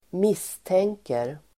Uttal: [²m'is:teng:ker]